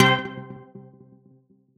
Synth Stab 13 (C).wav